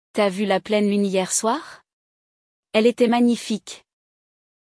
Escute o episódio de hoje para ouvir dois amigos conversando sobre isso em francês!
No episódio de hoje, vamos ouvir dois amigos conversando sobre a lua cheia.
Neste episódio, você vai escutar uma conversa espontânea e natural, absorvendo vocabulário útil e expressões que podem ser aplicadas no dia a dia.